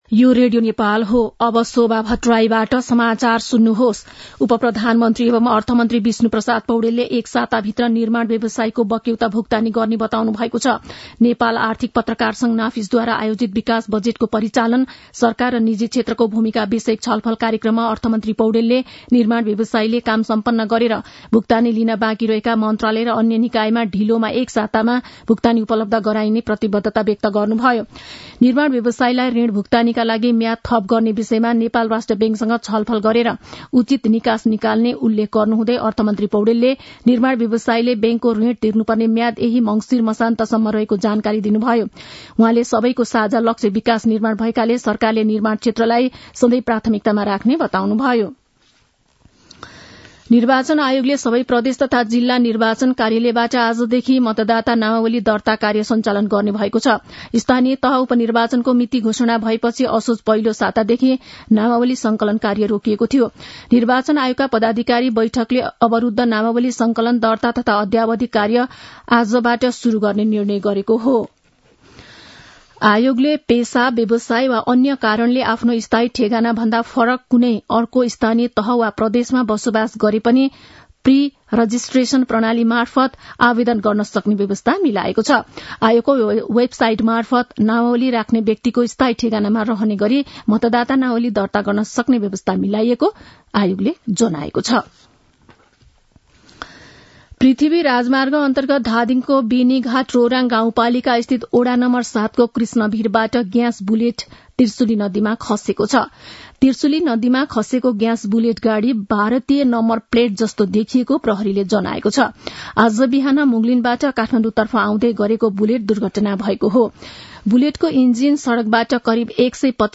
मध्यान्ह १२ बजेको नेपाली समाचार : २४ मंसिर , २०८१
12-am-nepali-news-1-5.mp3